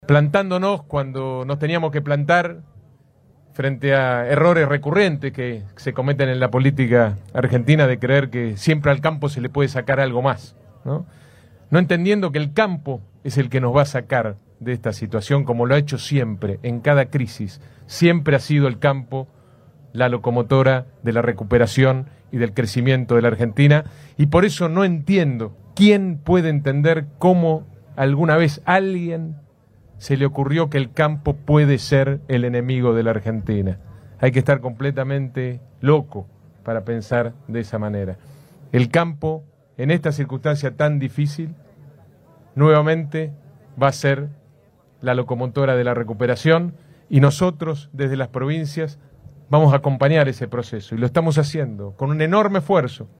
Los mandatarios de Santa Fe, Córdoba y Entre Ríos participaron este jueves del acto inaugural de la muestra Agroactiva, la exposición agropecuaria a cielo abierto más convocante de la Argentina y la región que se desarrolla hasta el 8 de junio en la ciudad de Amstrong.
Finalmente, el representante entrerriano, Rogelio Frigerio destacó que “el campo será nuevamente la locomotora que saque a la Argentina de esta situación”
AGROACTIVA-GOB-ENTRE-RIOS-FRIGERIO.mp3